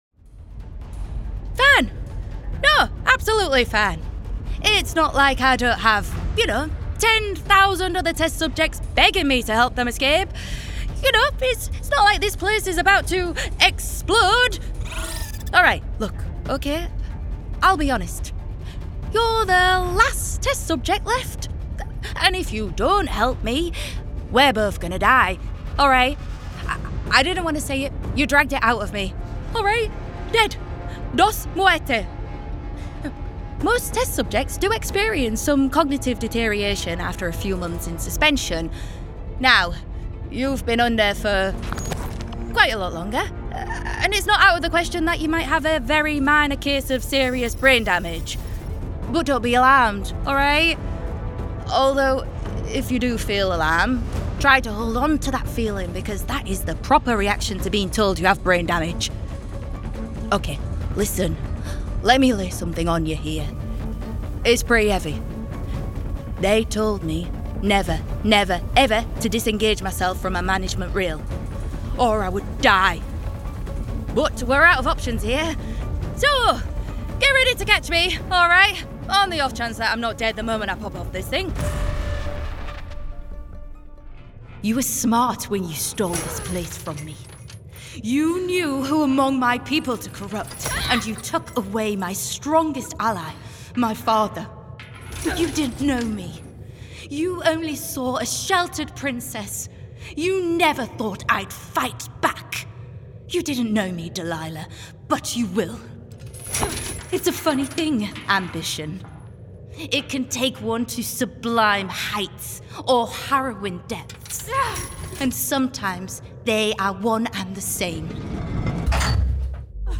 Video Game Showreel
Female
Hull - Yorkshire
Northern